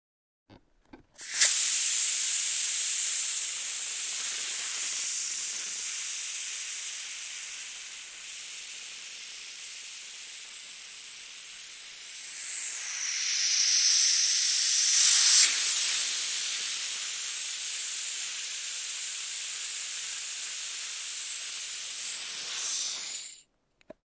Звуки воздуха
Футбольный мяч выпускают воздух